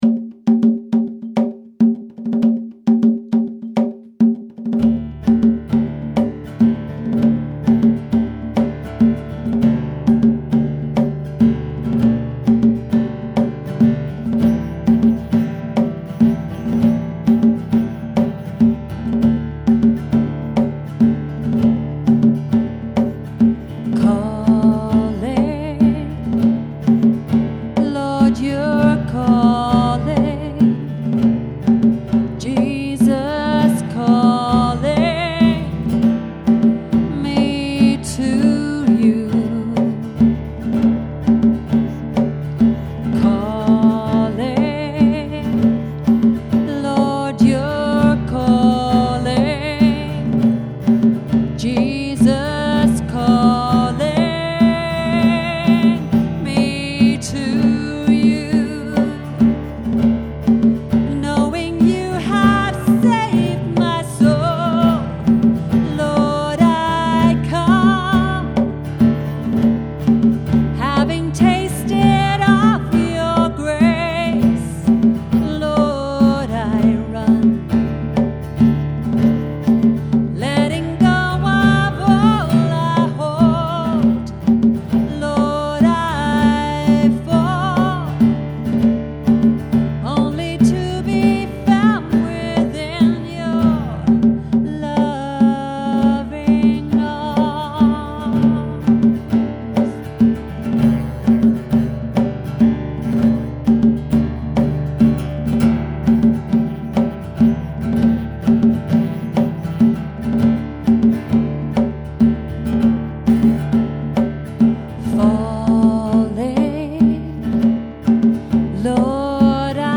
A simple acoustic version.
I normally do this in a dropped D tuning.…